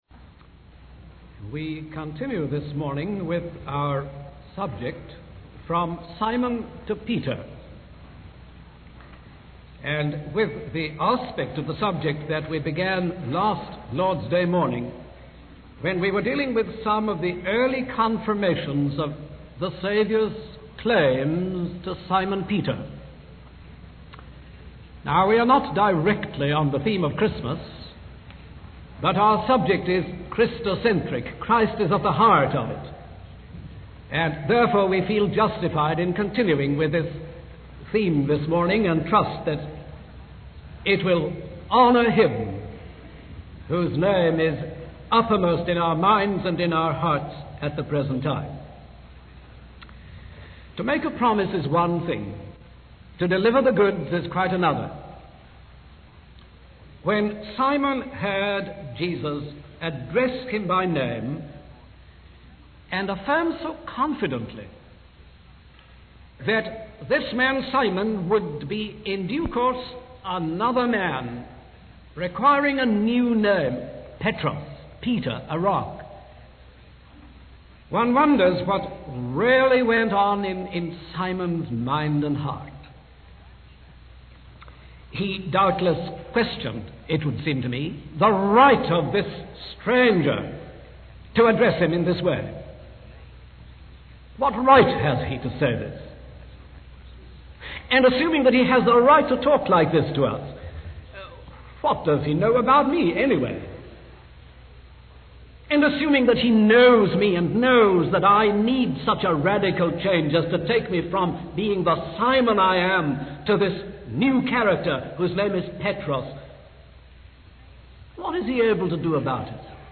In this sermon, the speaker emphasizes Jesus' unique perception of the need for change in people's lives.